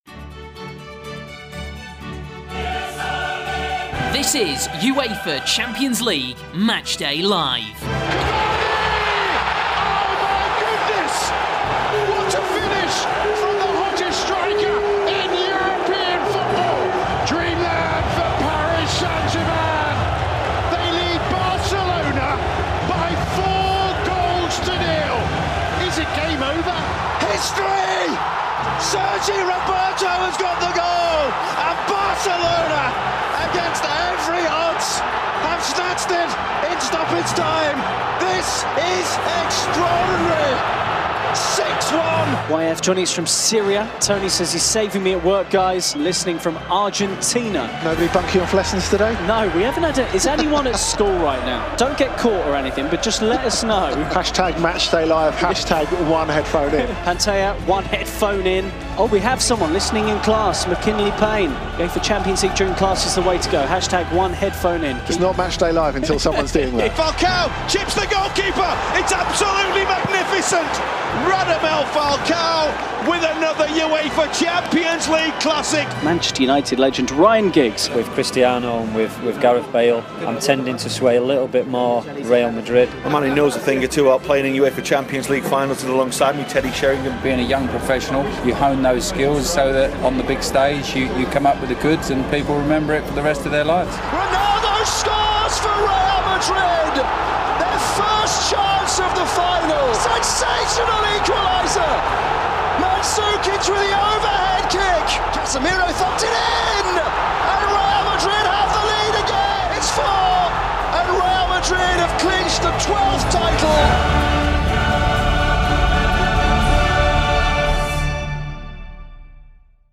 Facebook Twitter Headliner Embed Embed Code See more options Great live commentary on the UEFA Champions League's biggest matches, with all the goals from across Europe. Previews, analysis and reaction to every night of football in the competition.